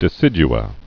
(dĭ-sĭj-ə)